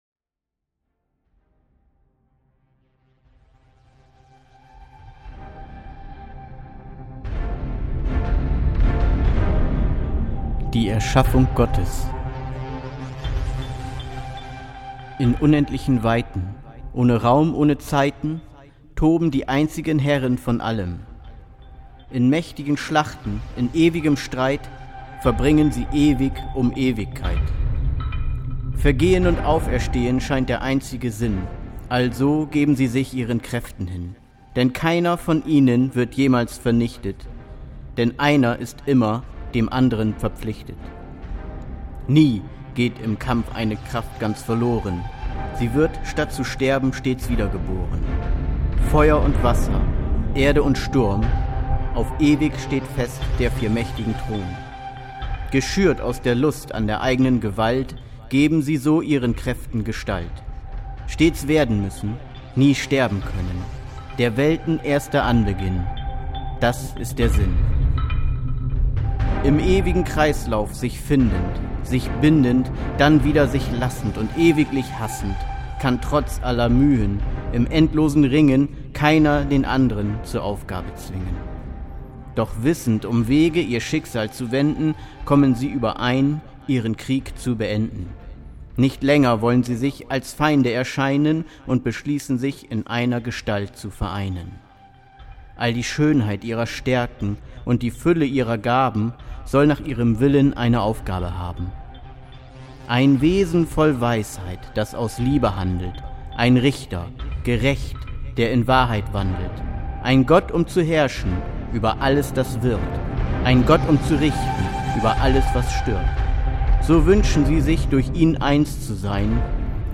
Musik M.K.S. „Epic”